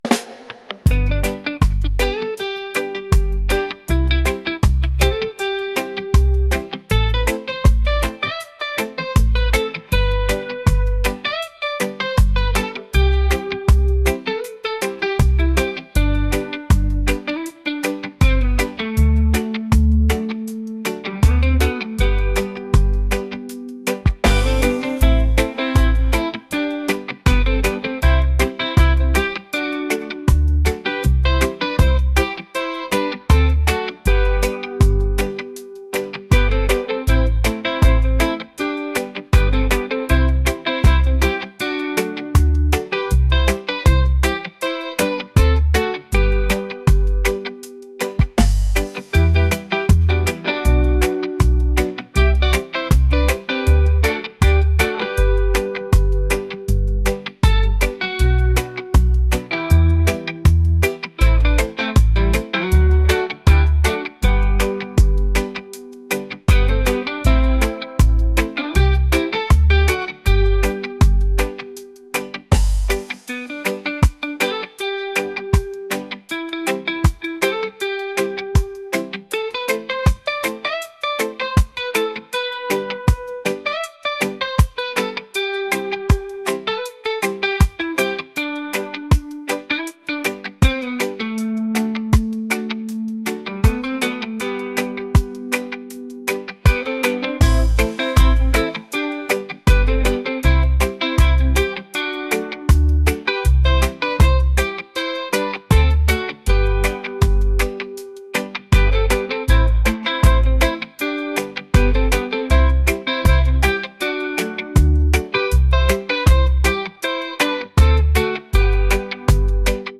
reggae | lounge | folk